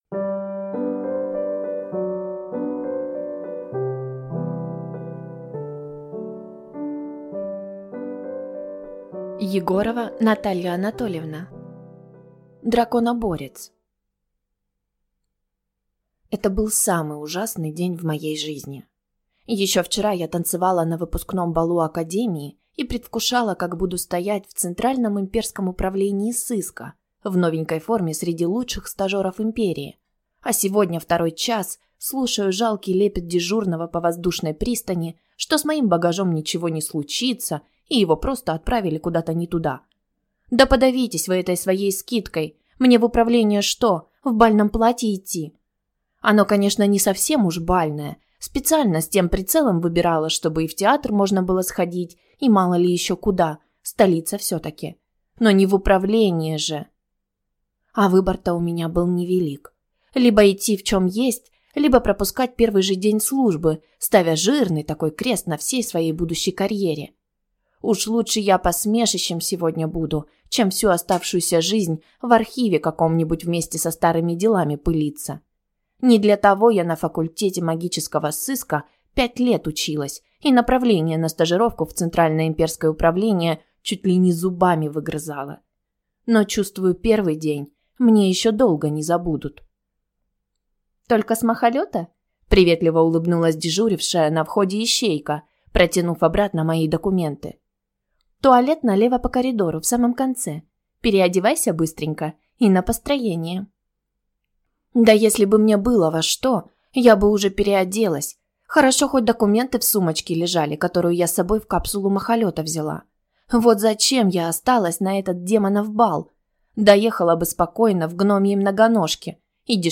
Аудиокнига Драконоборец | Библиотека аудиокниг
Прослушать и бесплатно скачать фрагмент аудиокниги